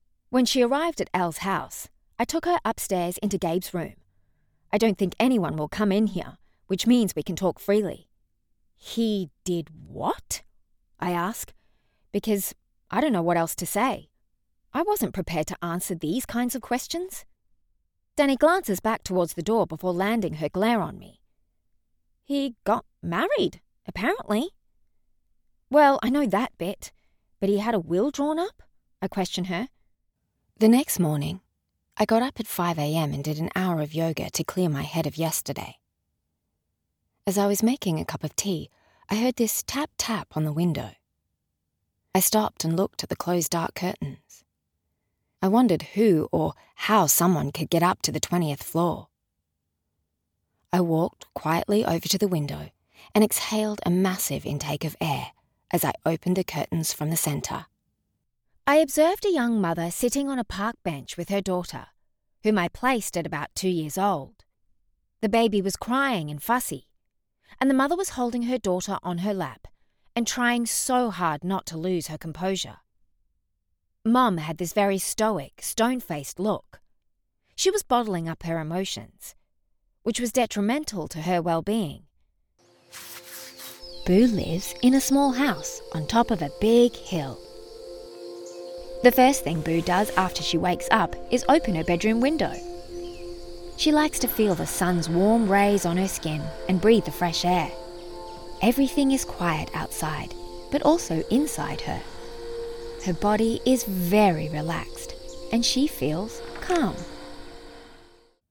Female
English (Australian)
Audiobooks
A Few Different Audiobooks
Words that describe my voice are Natural, Explainer, Conversational.
0902Audiobook_demo.mp3